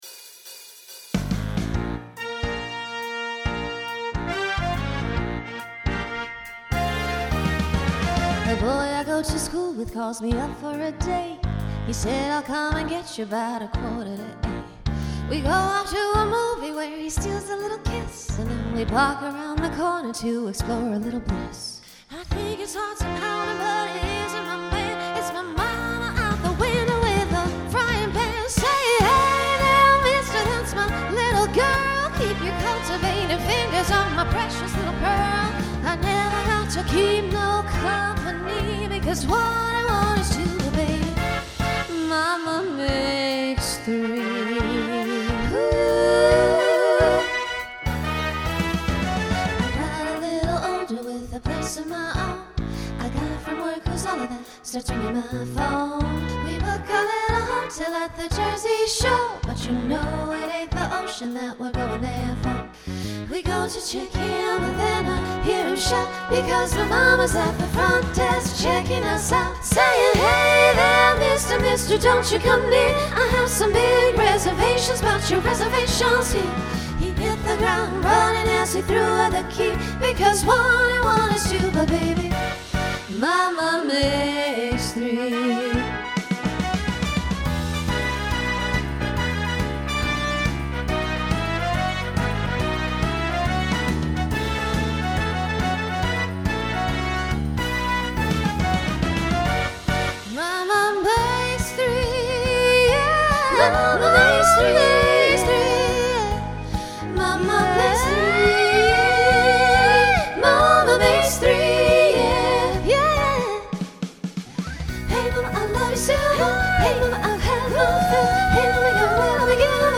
Genre Broadway/Film Instrumental combo
Transition Voicing SSA